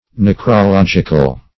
Necrologic \Nec`ro*log"ic\, Necrological \Nec`ro*log"ic*al\, a.